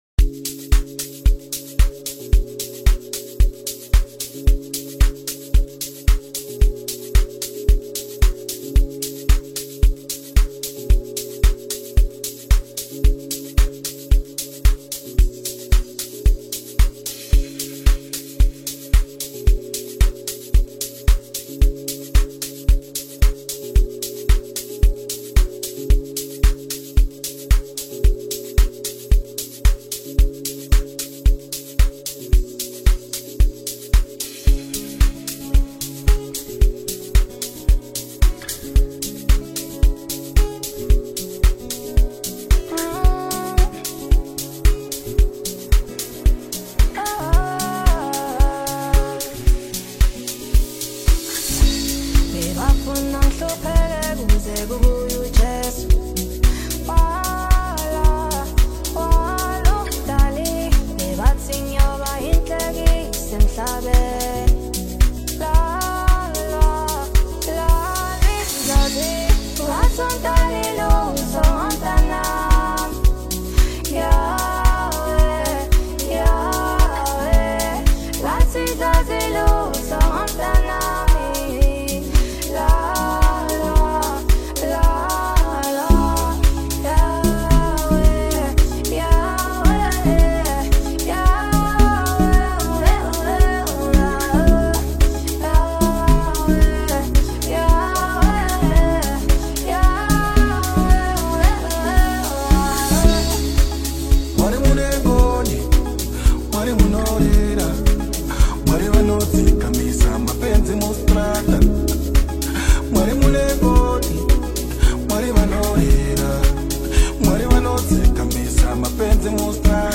Amapiano
Gospel